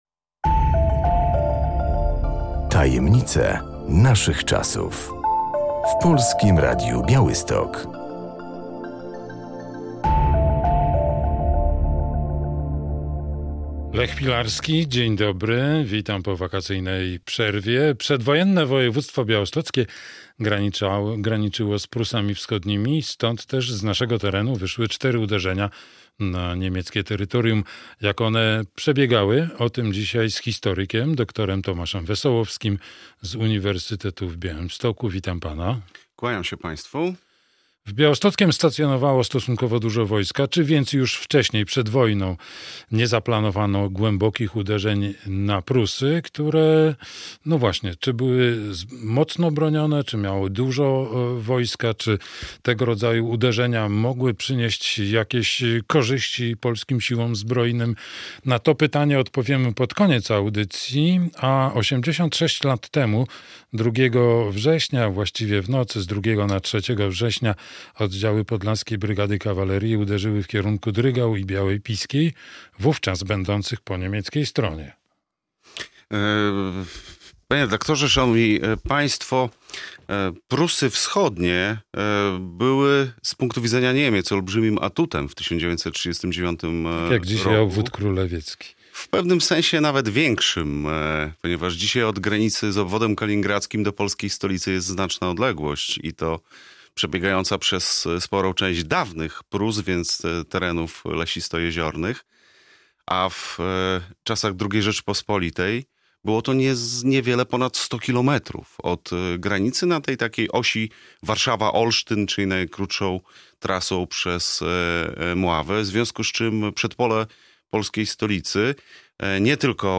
Przedwojenne województwo białostockie graniczyło z Prusami Wschodnimi. Stąd też wyszły cztery uderzenia na niemieckie tereny. Jak one przebiegały? O tym rozmawiamy z historykiem